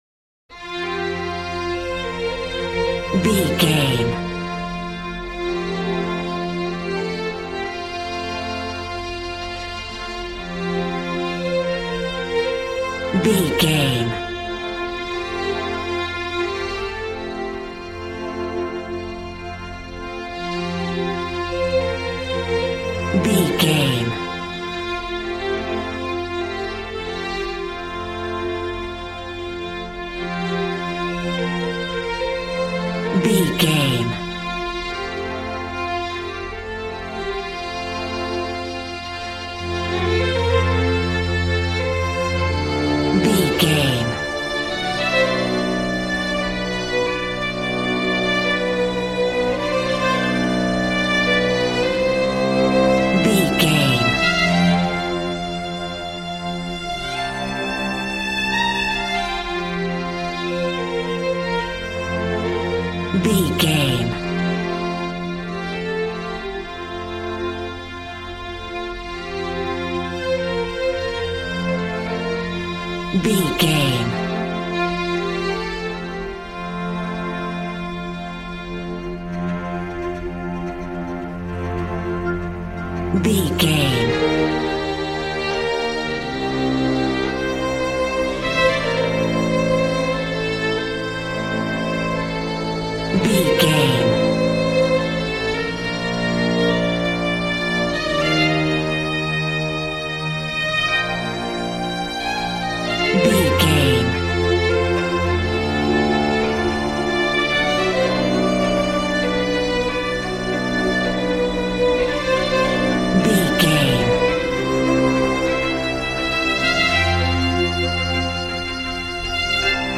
Aeolian/Minor
regal
brass